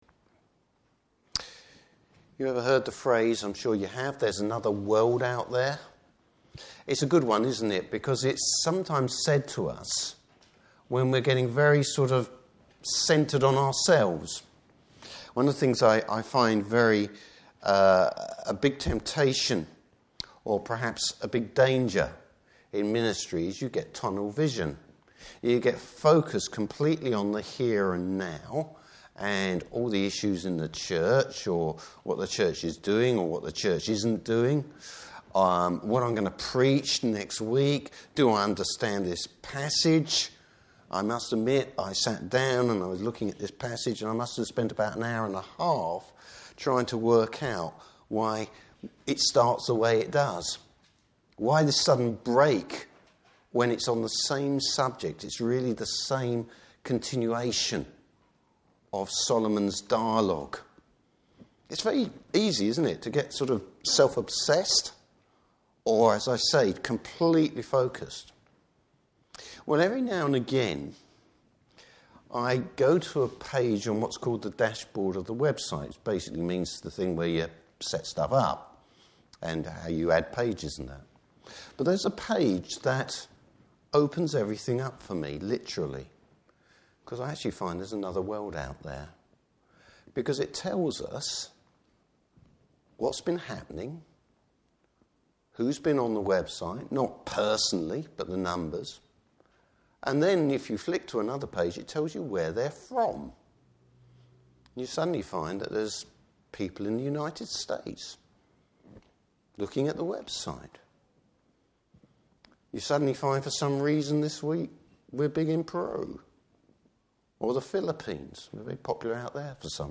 Service Type: Morning Service Bible Text: Ecclesiastes 3.